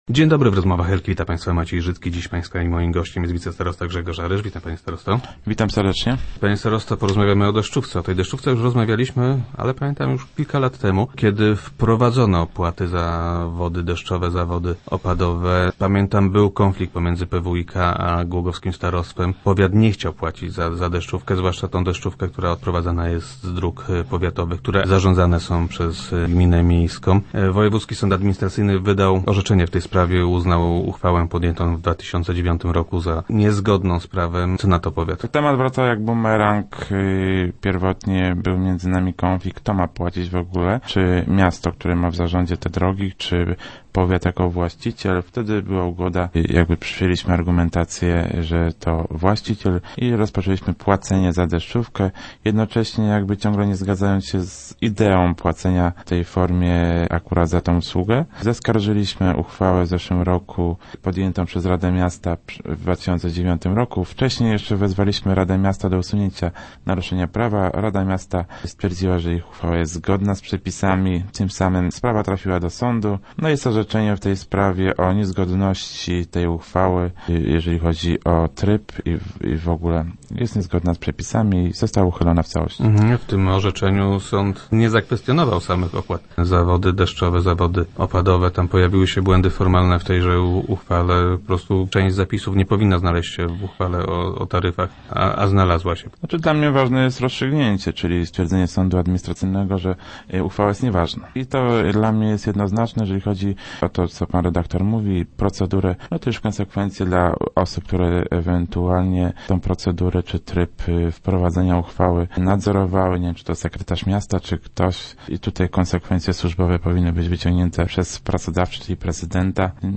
Jak powiedział wicestarosta w Rozmowach Elki, złożenie wniosku do PWiK o zwrot pieniędzy, jakie powiat zapłacił za deszczówkę w 2011 roku, to kwestia tygodni.